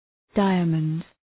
Προφορά
{‘daımənd}